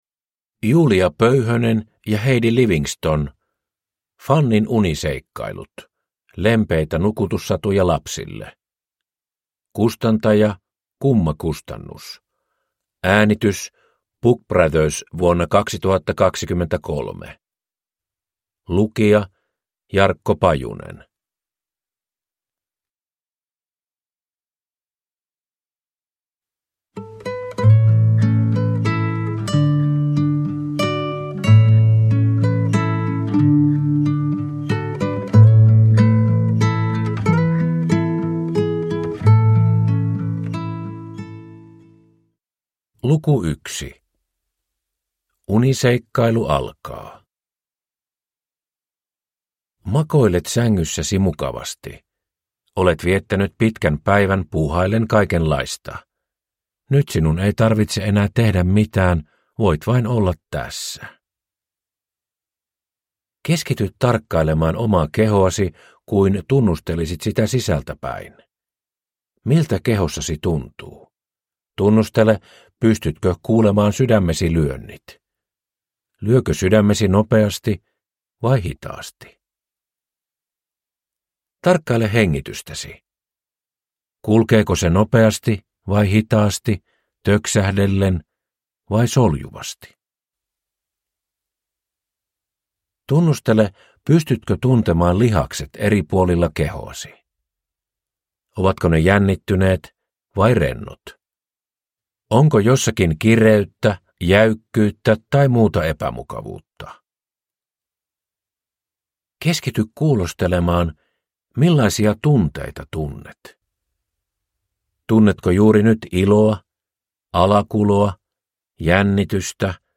Fannin uniseikkailut – Ljudbok – Laddas ner